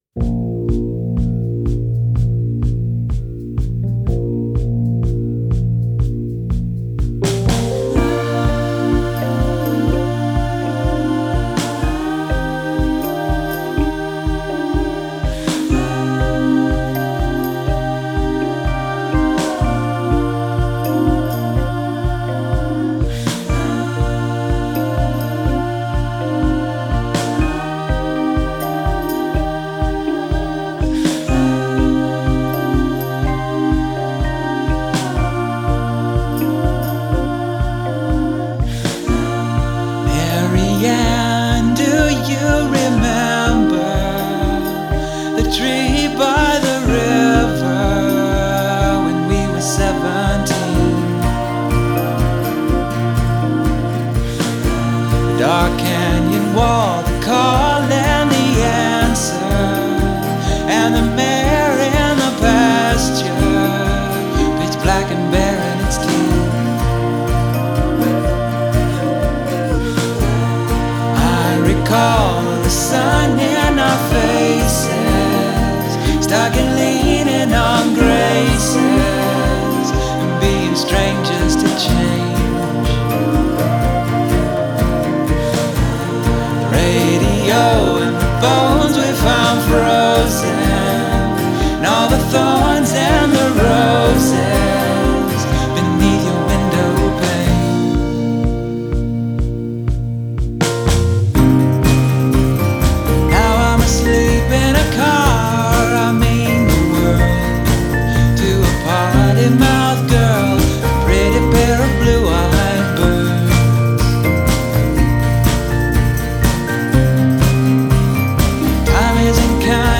visti gli echi Seventies di questo nuovo lavoro.